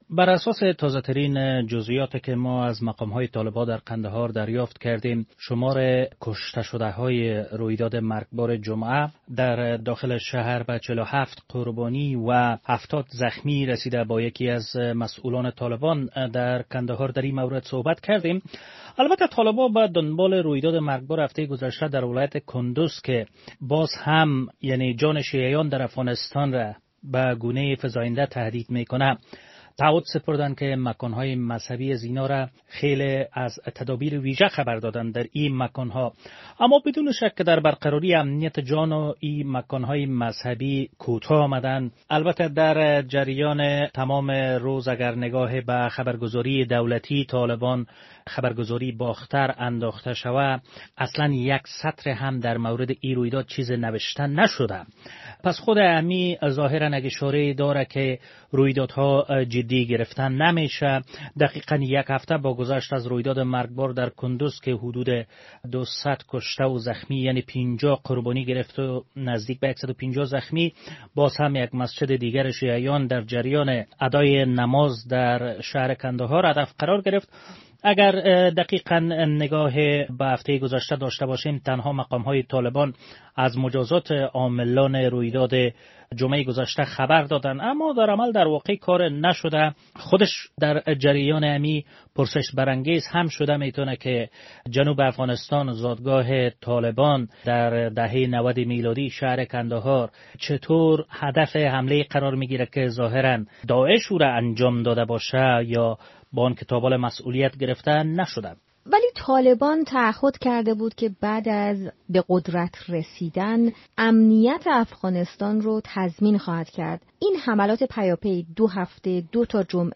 خبرها و گزارش‌ها